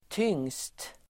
tyngst , heaviestUttal: [tyng:st] Se tung